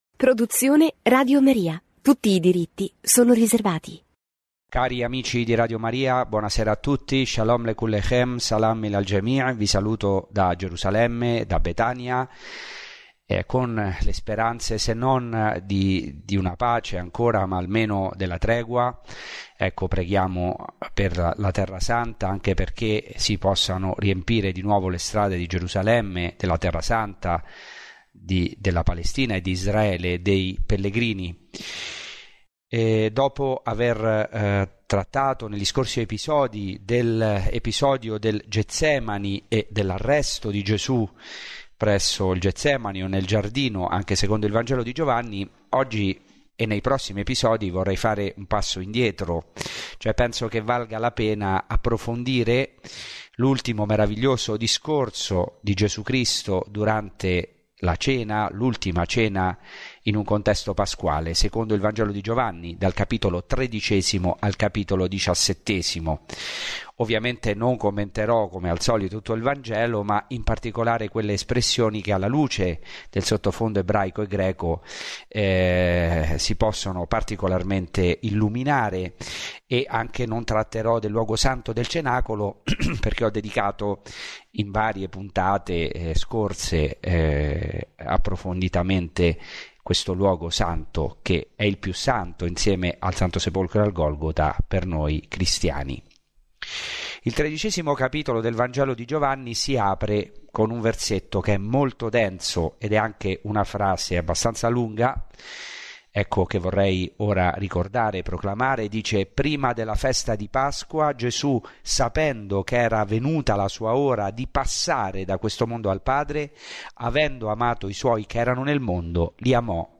Ciclo di catechesi